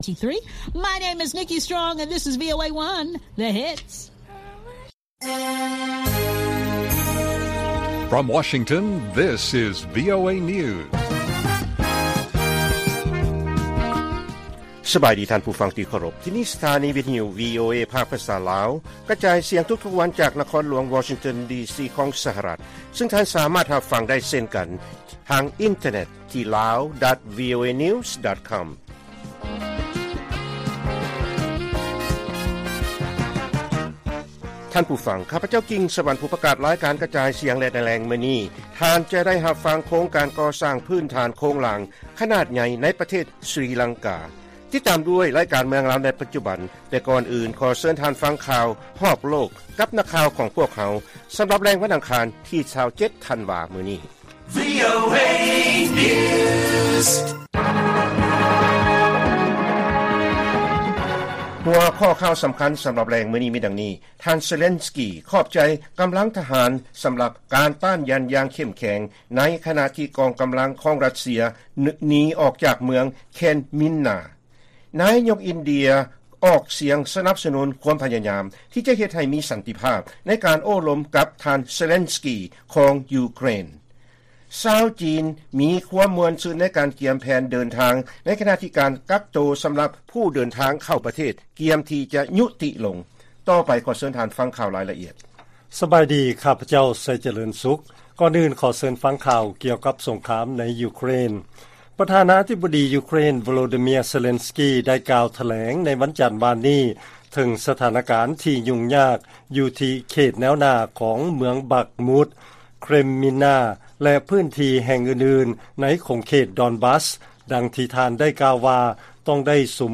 ລາຍການກະຈາຍສຽງຂອງວີໂອເອ ລາວ: ທ່ານເຊເລັນສກີ ຂອບໃຈ ກຳລັງທະຫານ ສຳລັບການຕ້ານຢັນຢ່າງເຂັ້ມແຂງ ຂະນະທີ່ກອງກຳລັງຂອງຣັດເຊຍ ໜີອອກຈາກເມືອງເຄຣມີນນາ